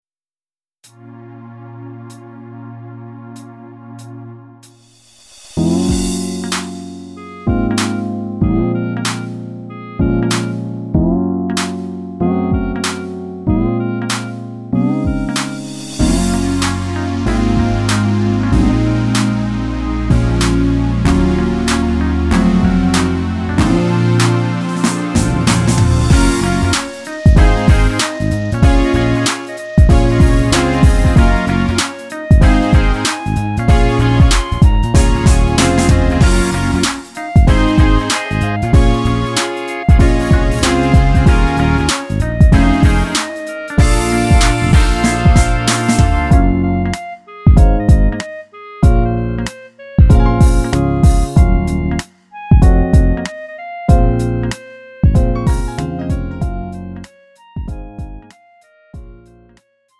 음정 -1키 3:11
장르 가요 구분